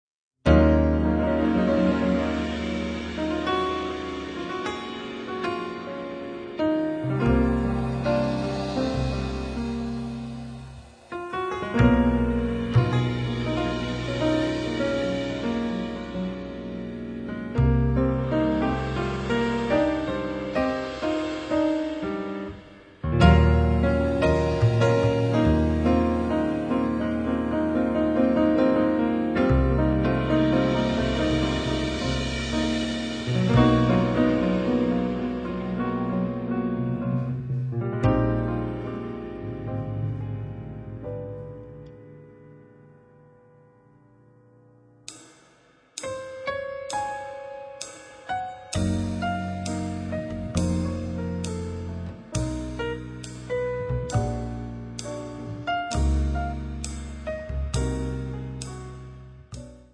pianoforte
contrabbasso
batteria
belle note pesate